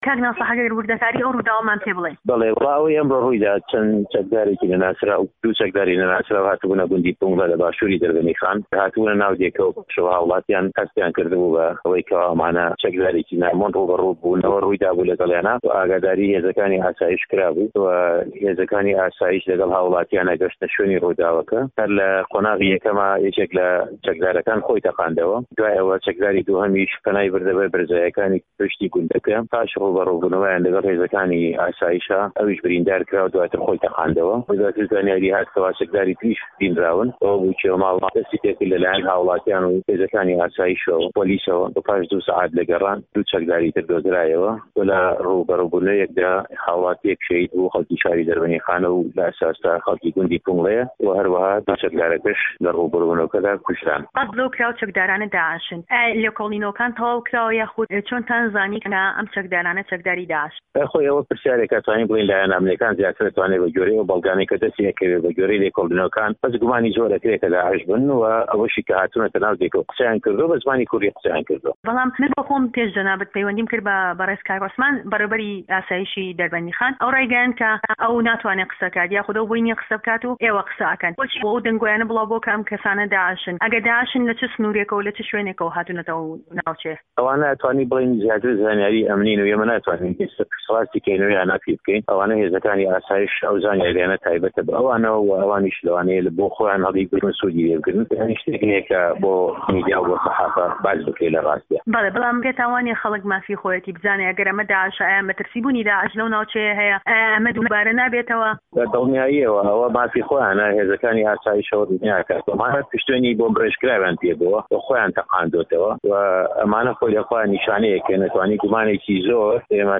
ناسیح مه‌لا حه‌سه‌ن قایمقامی ده‌ربه‌ندیخان له‌میانی وتوێژێكدا له‌گه‌ڵ به‌شی كوردی ده‌نگی ئه‌مەریكا باس له‌ ڕووداوه‌كه‌ی ئه‌مڕۆی شارۆچكه‌ی ده‌ربه‌ندیخان ده‌كات و ده‌ڵێت، گومان ده‌كرێت چه‌كداری داعش بوبێتن ئه‌و چه‌كدارانه‌.